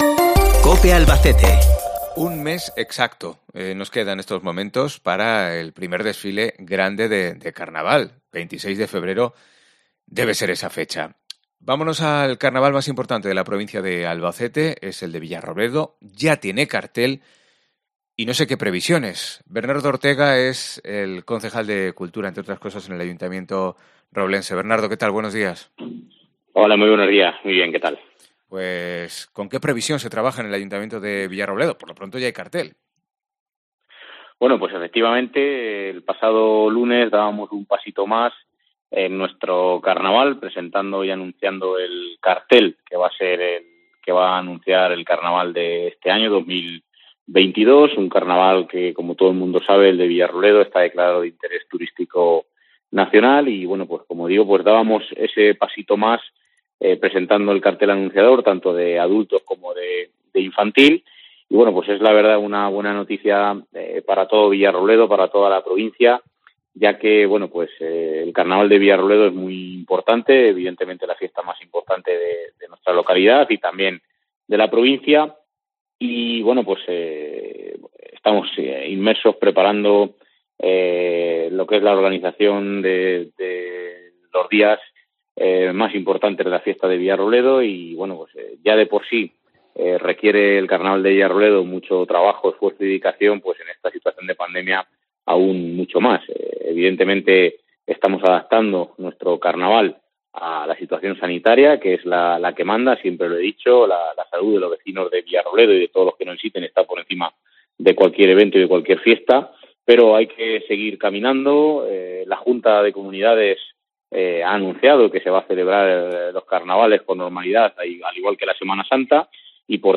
ENTREVISTA COPE
Bernardo Ortega, concejal de Cultura de Villarrobledo